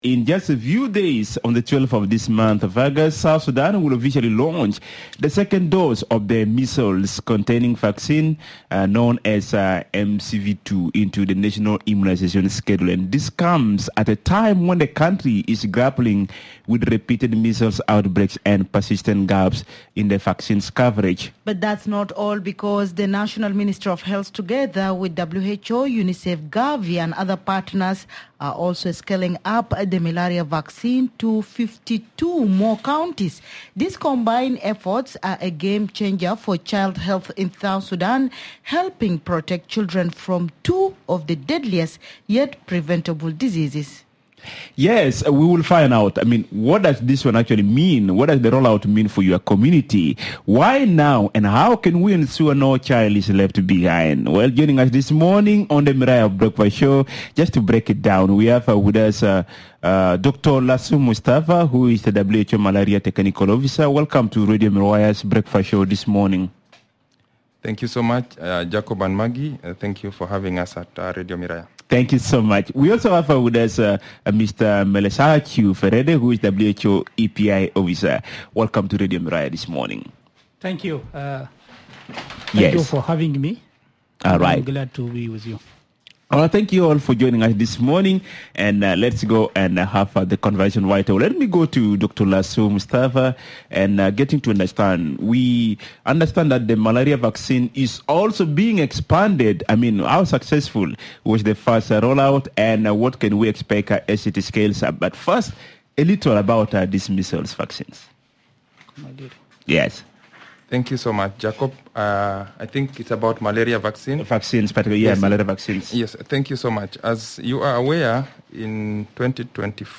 Radio Miraya is in conversation with: